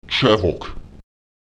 aw [Q] wird wie das ä in Kälte artikuliert.